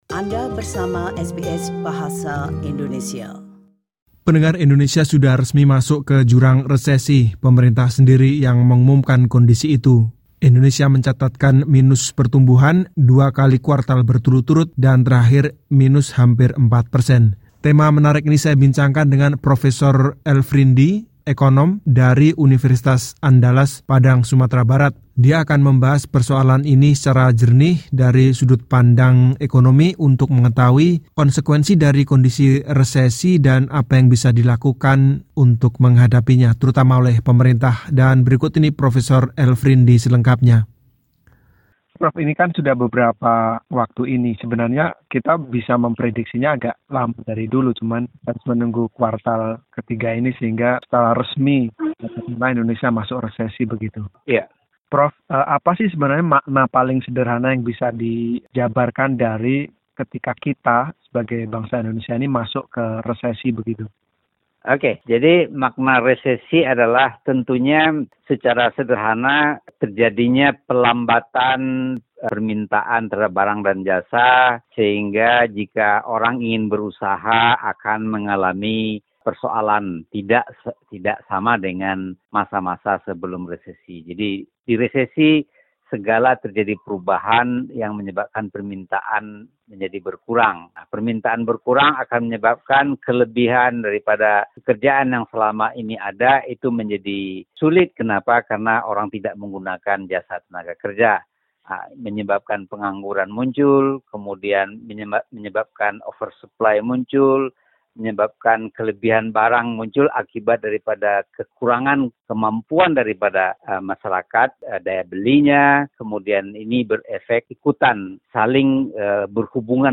perbincangan